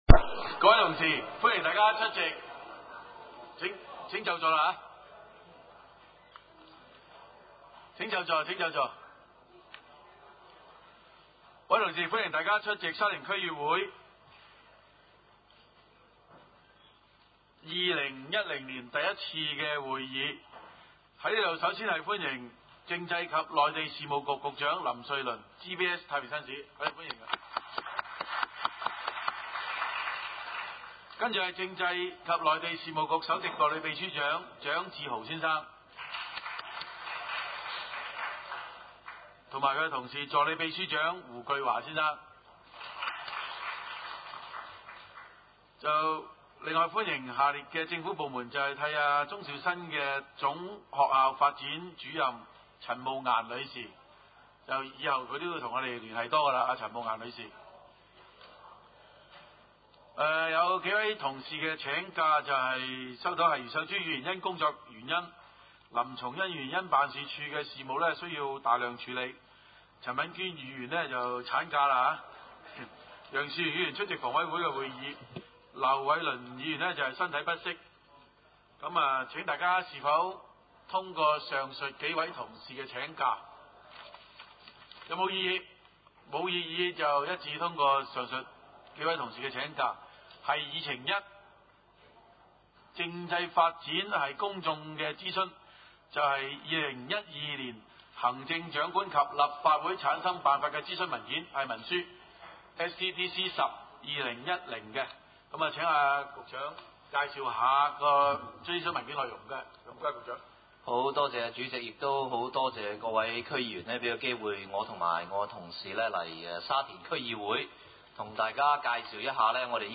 沙田區議會 二零一零年第一次會議 日期：二零一零年一月二十一日 時間：下午二時三十分 地點：沙田區議會會議室 議 程 討論時間 1.